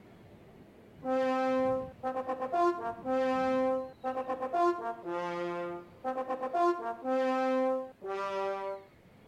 Flute
Plays a tune on the flute for 8 seconds.